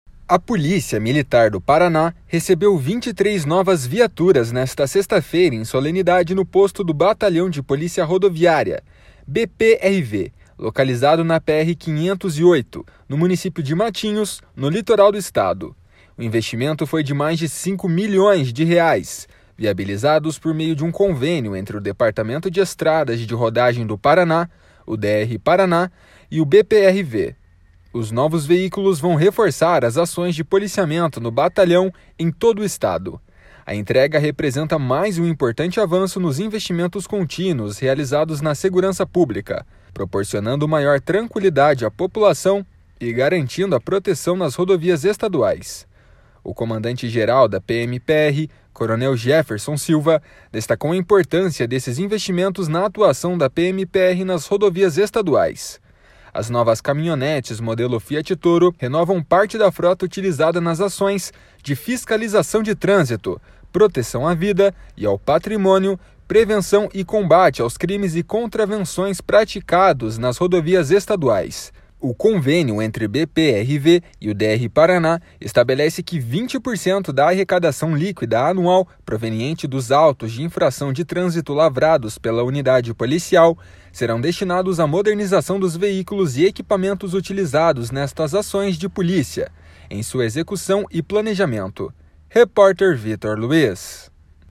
O comandante-geral da PMPR, coronel Jefferson Silva, destacou a importância desses investimentos na atuação da PMPR nas rodovias estaduais.